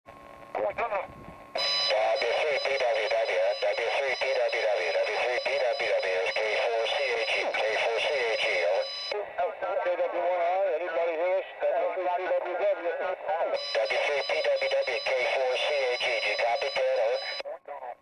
Radio squads were deployed to the summit of Big Pocono Mountain for this year's field exercise.
The rest are off the air via the  GRC-9 receiver.